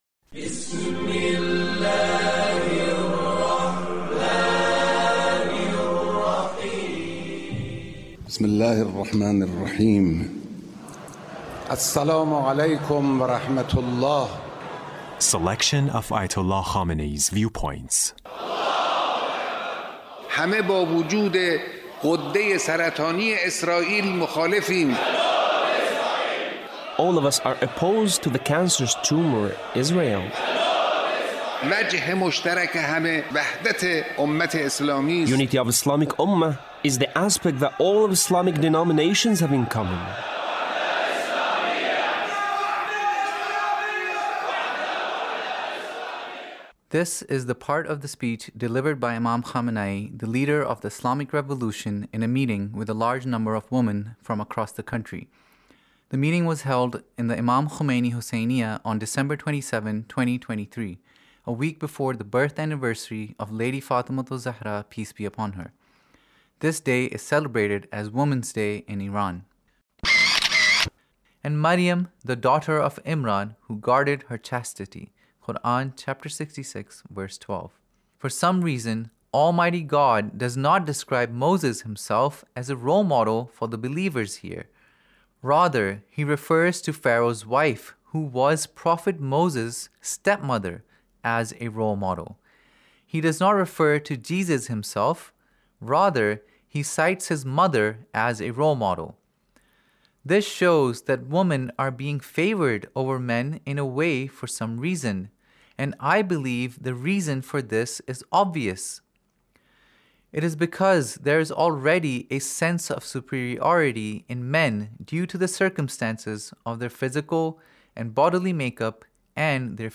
Leader's Speech in a meeting with Ladies on Women's Day